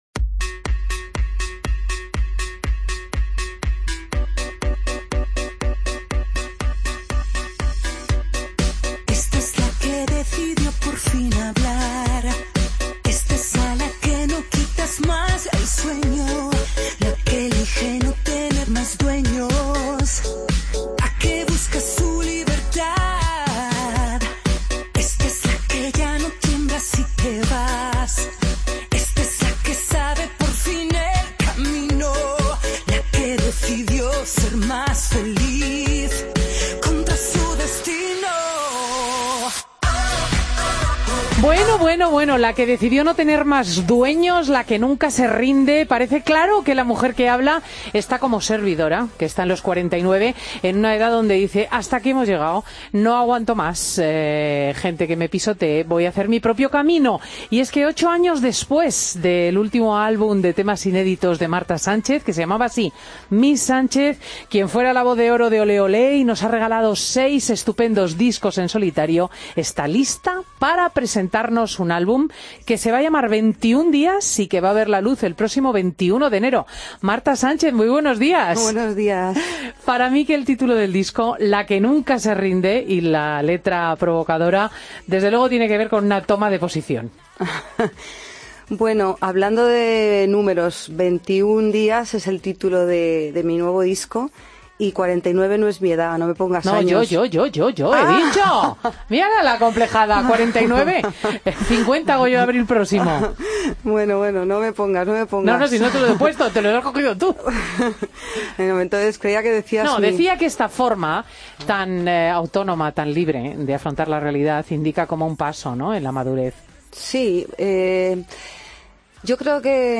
Entrev ista a Marta Sánchez en Fin de Semana COPE
Entrevistas en Fin de Semana